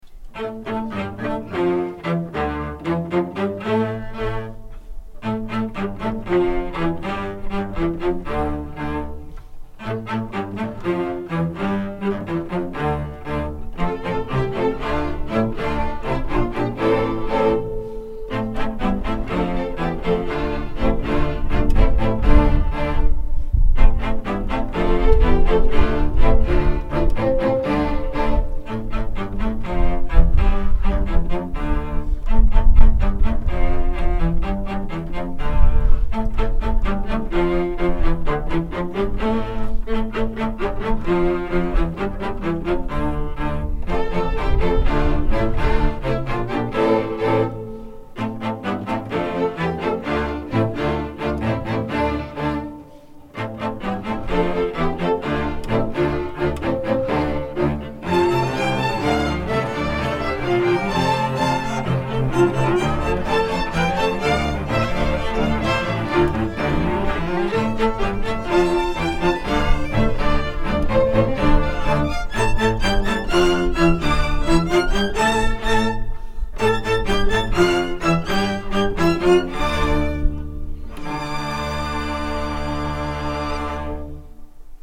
“Riu Chiu Chiu” για Σοπράνο και Ορχήστρα Εγχόρδων (live)